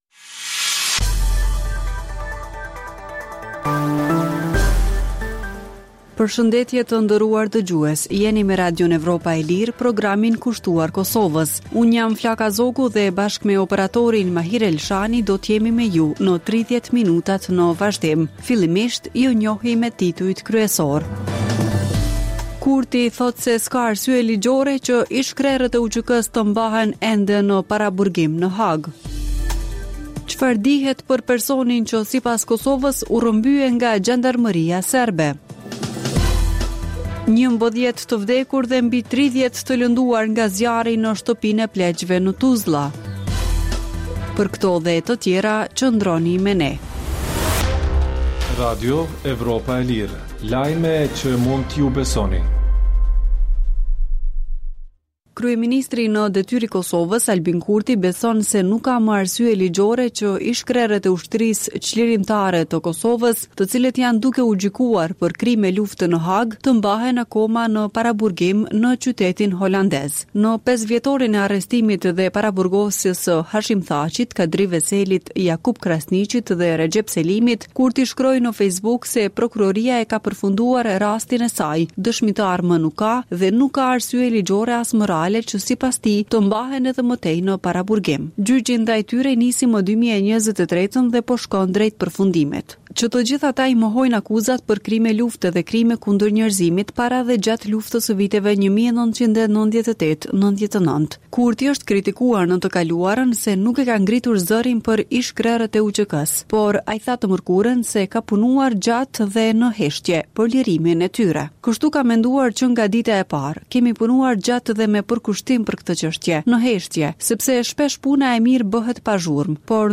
Emisioni i orës 16:00 është rrumbullakësim i zhvillimeve ditore në Kosovë, rajon dhe botë. Rëndom fillon me buletinin e lajmeve dhe vazhdon me kronikat për zhvillimet kryesore të ditës. Në këtë edicion sjellim edhe intervista me analistë vendës dhe ndërkombëtarë.